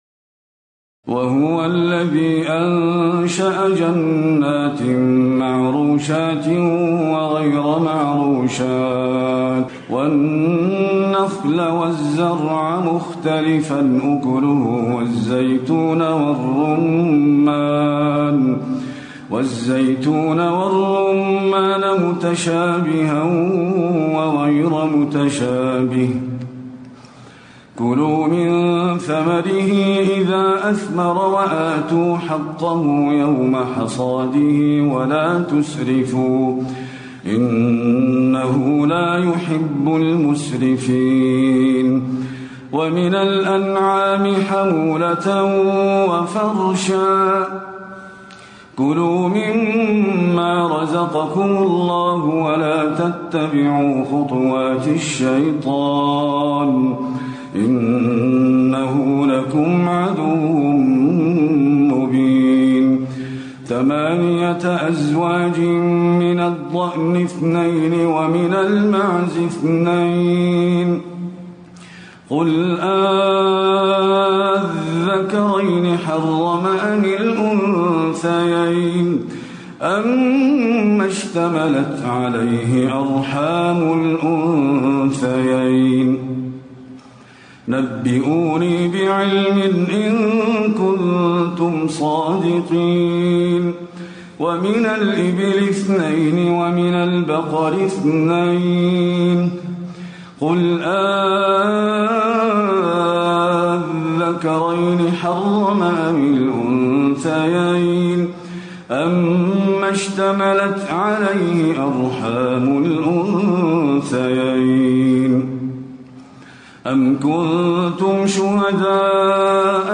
تراويح الليلة الثامنة رمضان 1437هـ من سورة الأنعام (141-165) و الأعراف (1-64) Taraweeh 8 st night Ramadan 1437H from Surah Al-An’aam and Al-A’raf > تراويح الحرم النبوي عام 1437 🕌 > التراويح - تلاوات الحرمين